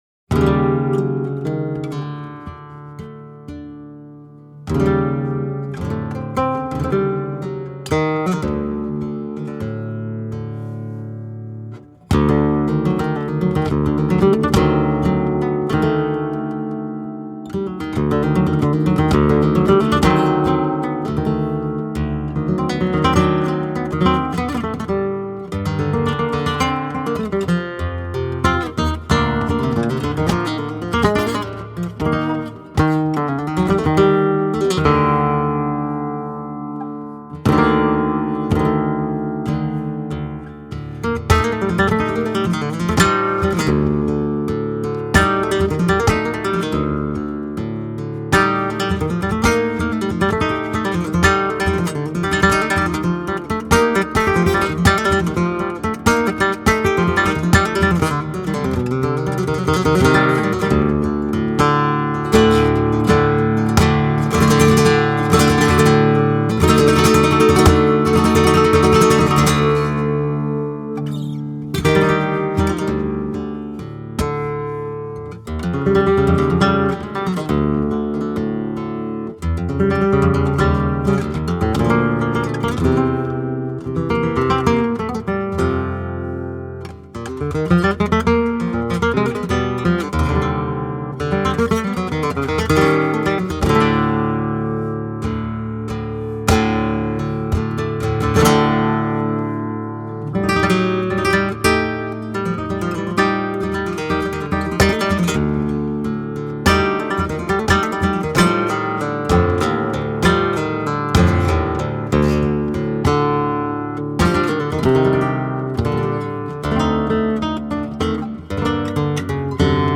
composition et guitare